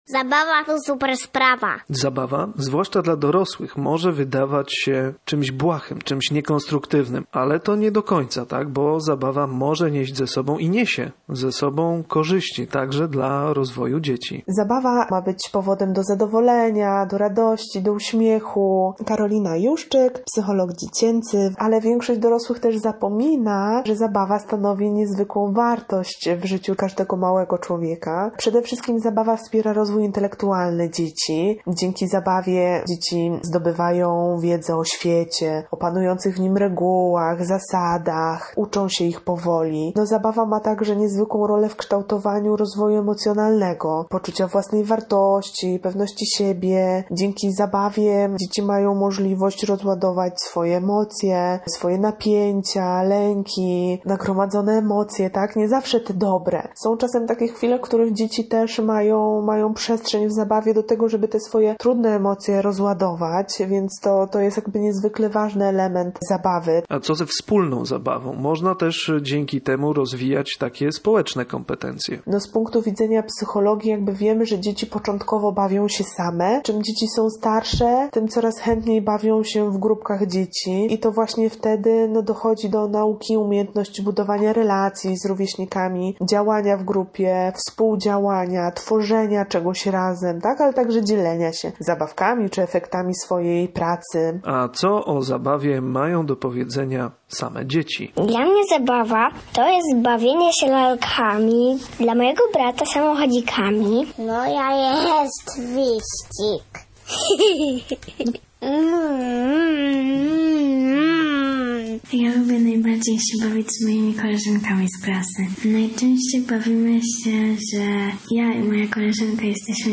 Głos zabierają także dzieci!